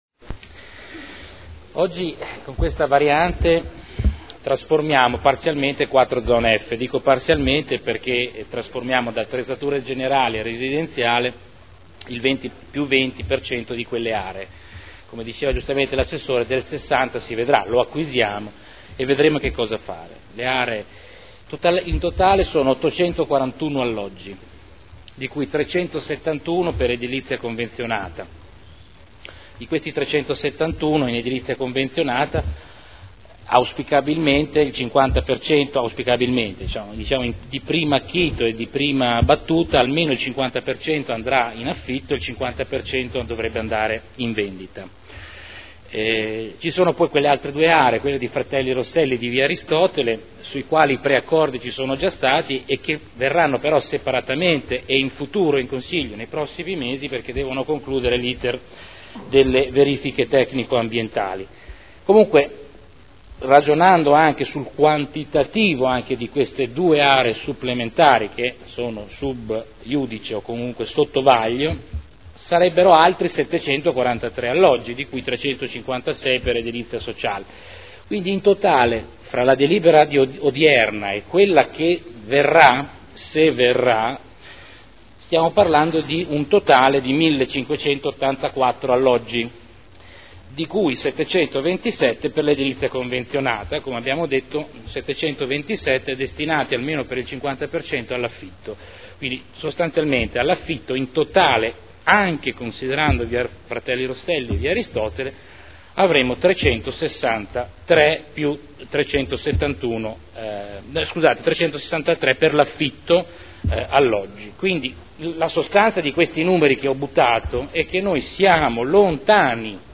Ordine del giorno n° 37644 Pianificazione e riqualificazione della città. Dibattito